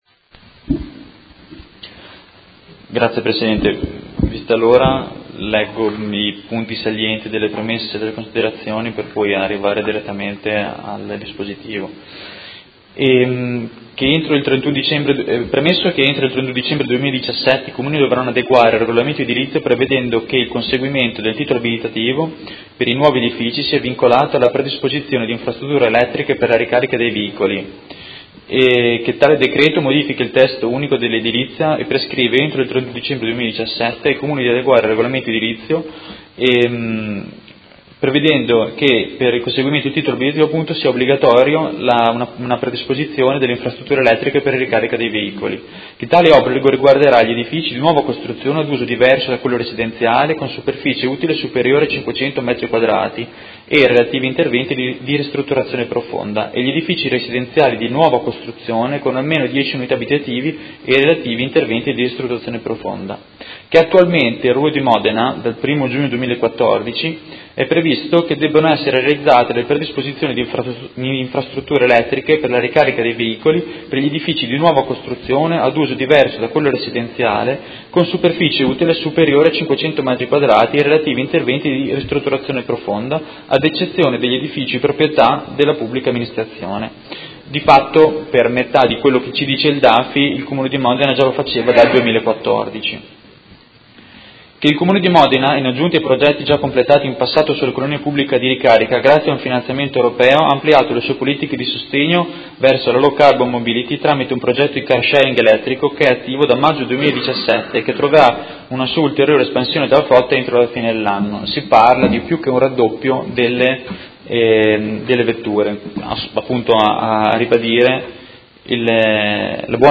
Seduta del 23/11/2017 Odg presentato in data odierna nr. 177701. Sostegno alla low carbon mobility.